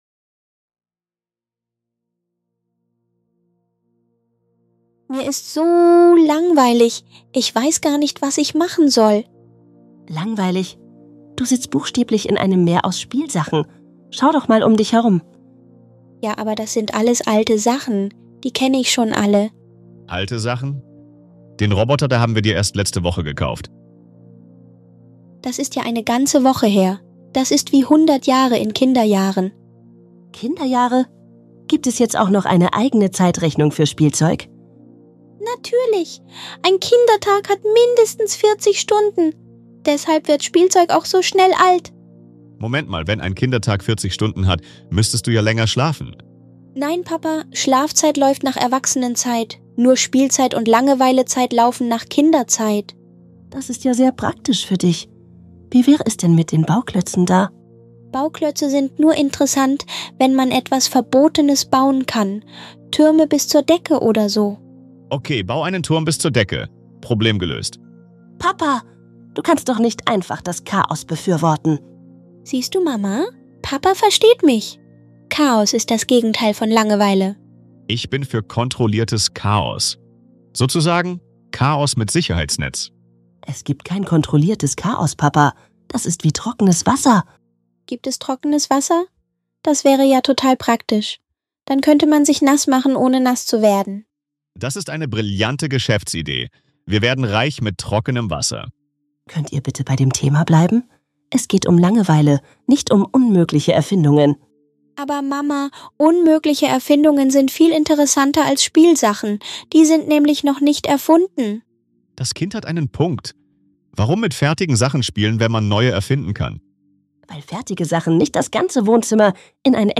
In diesem lustigen Gespräch zwischen einem